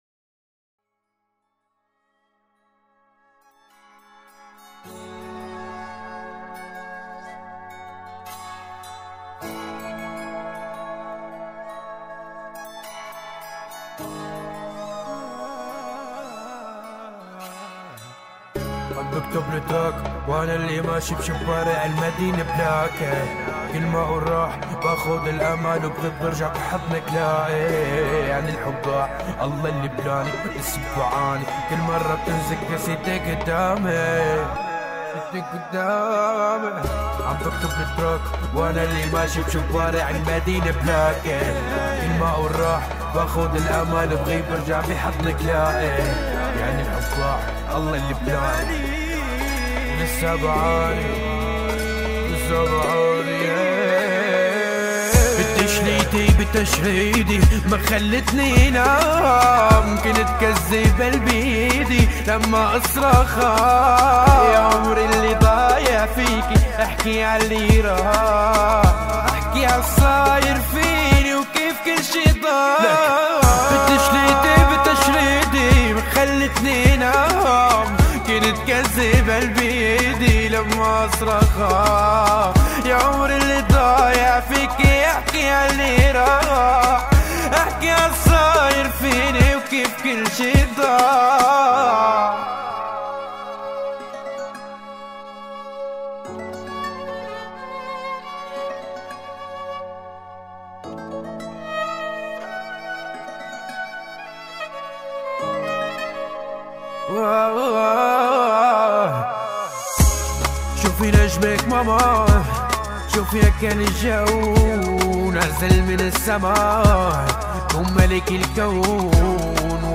ظاهرة موسيقية تمزج بين الأصالة والمعاصرة
مثل العود والقانون إلى جانب الإيقاعات الإلكترونية.